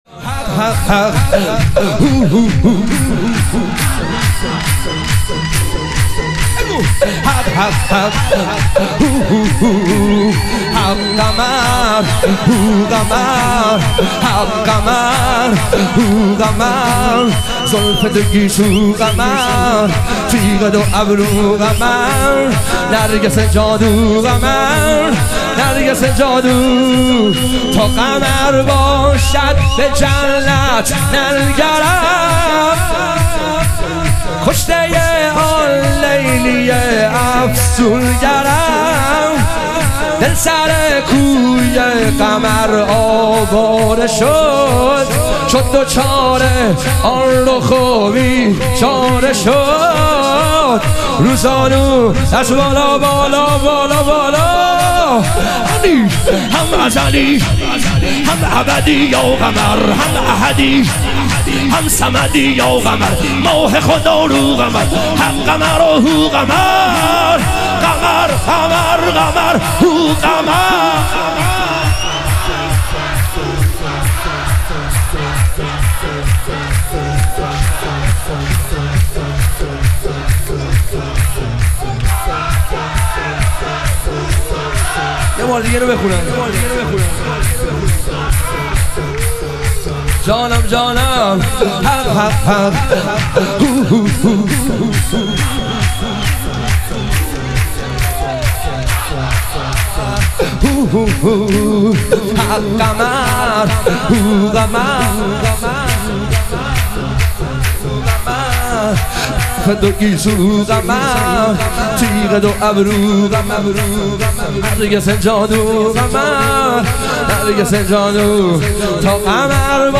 ظهور وجود مقدس حضرت عباس علیه السلام - شور